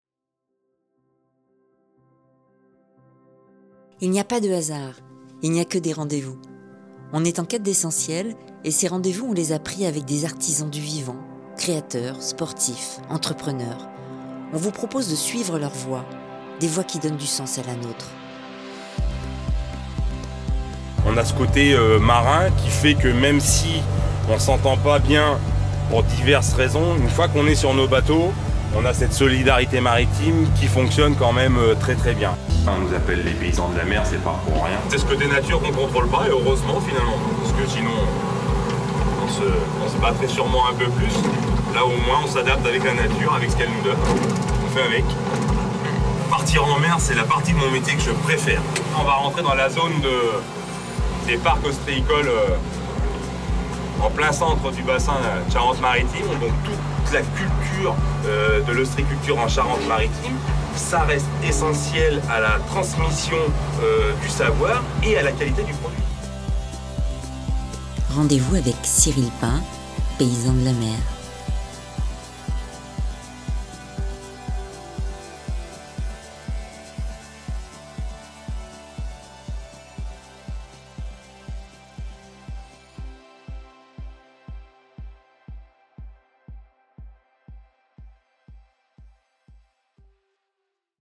Ici, sur l’Île d’Oléron.